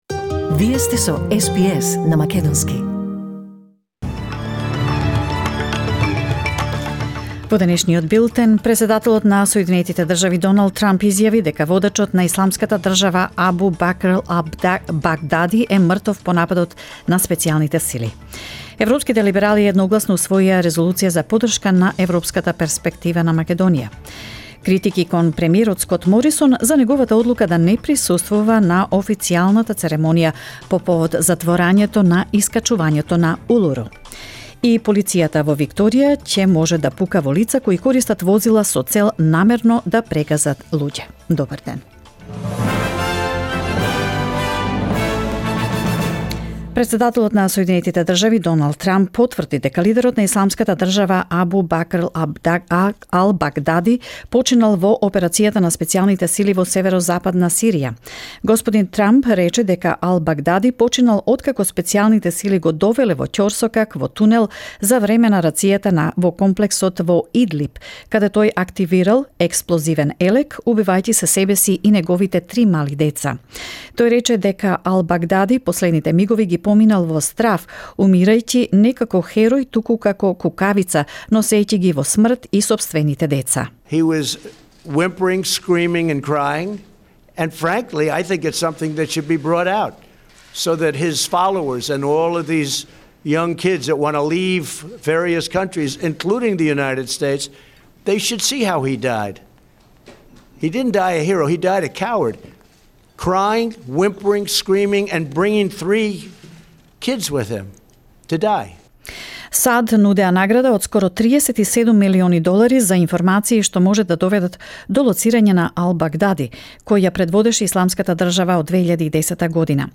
SBS News in Macedonian 28 October 2019